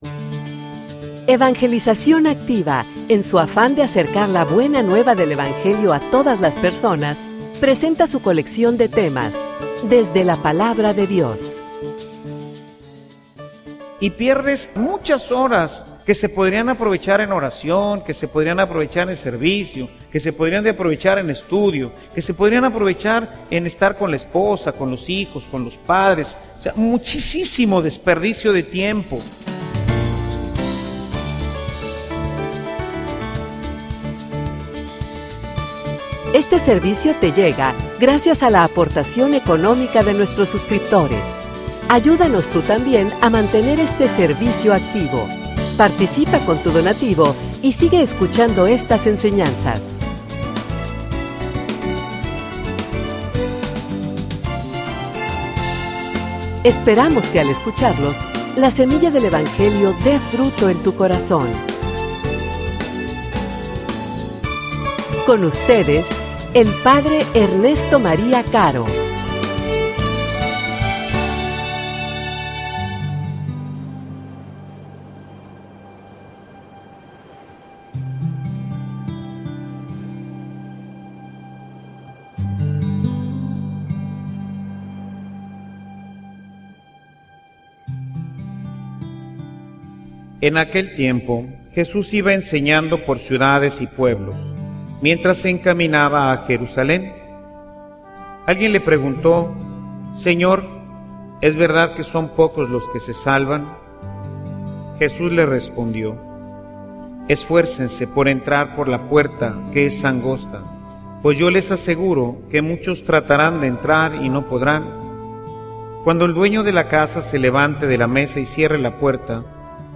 homilia_Esfuercense_mas.mp3